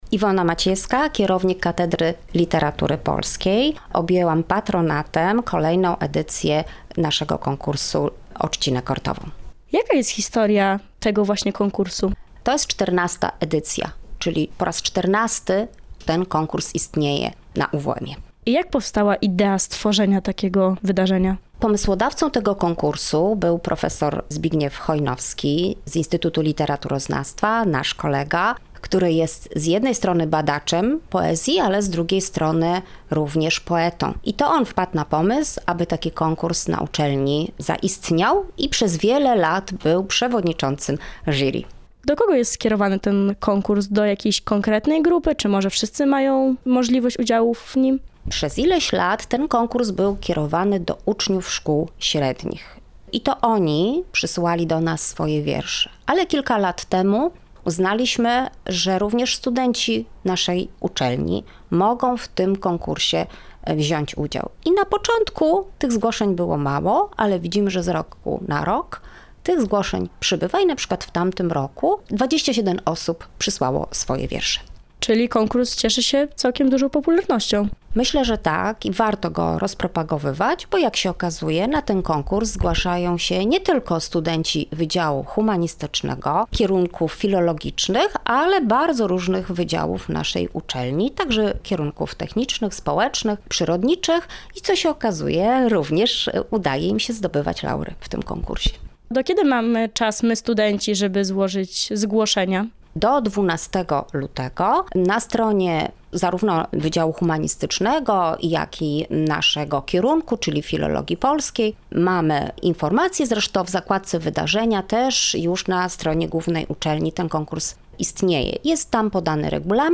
RADIO UWM FM